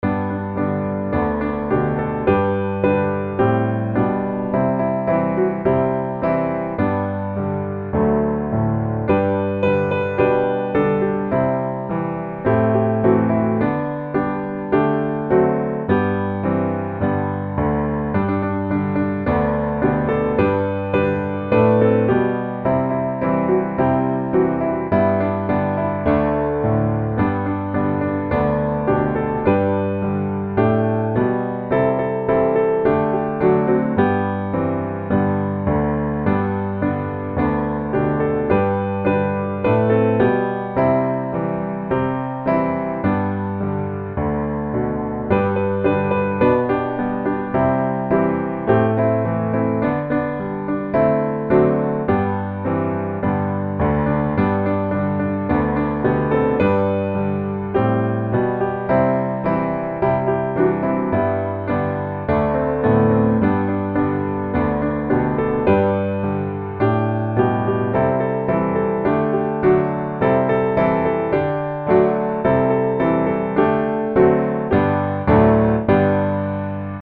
G Major